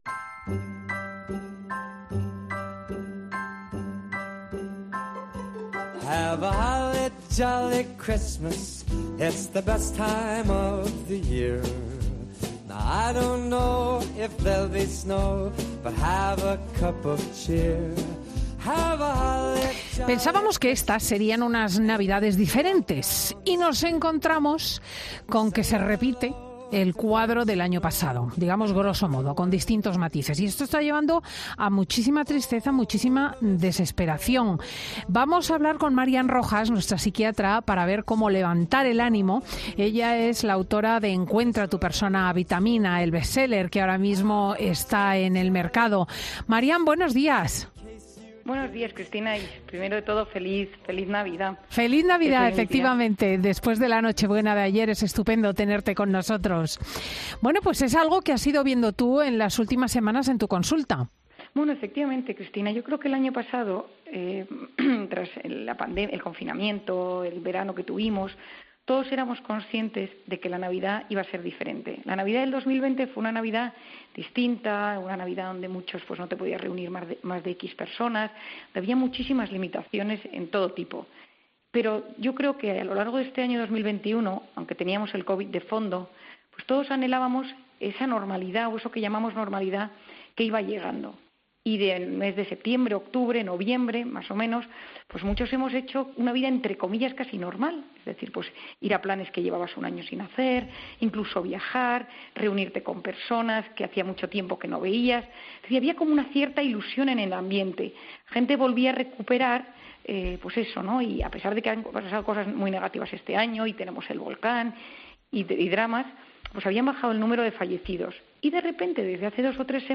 La psiquiatra, autora de 'Encuentra tu persona vitamina', explica en Fin de Semana con Cristina cómo pasar, de la mejor forma posible, estas fechas tan señaladas y familiares